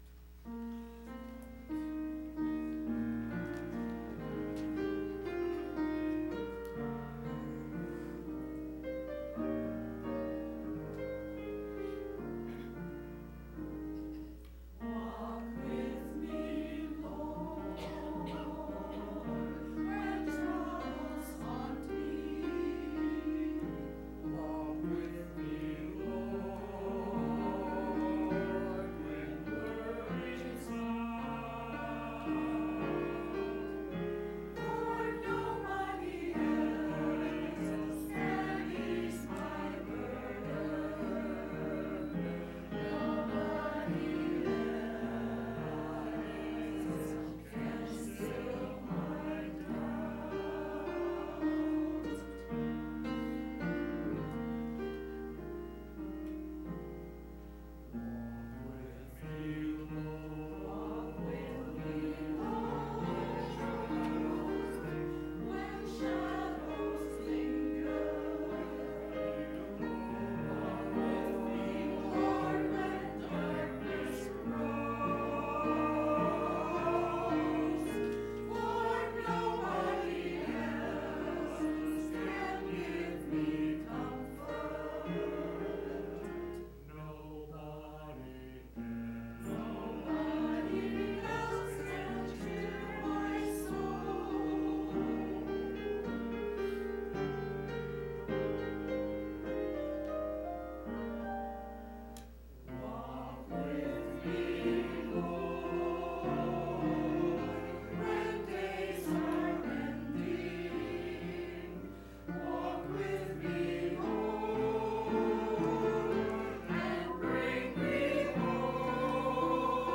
Church Choir – Walk With Me, Lord 11.10.19
To hear the church choir praise God with music please click play below.